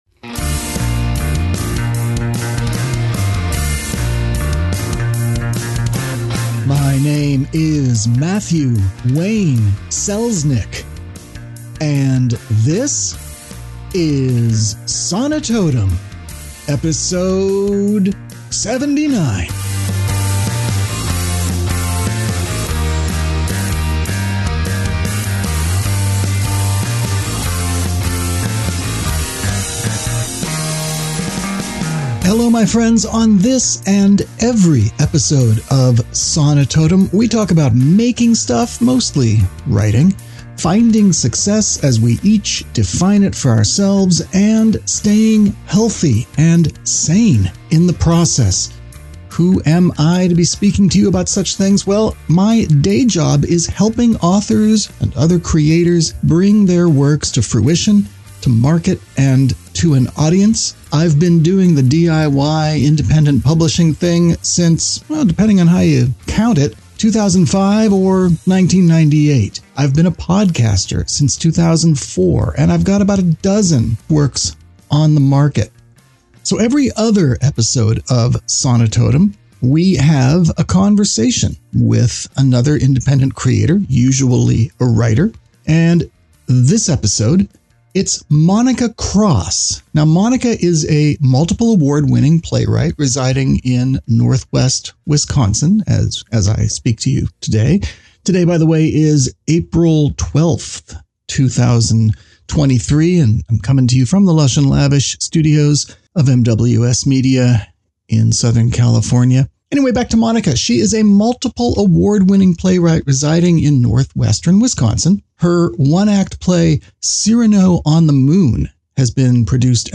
Sonitotum 079: In Conversation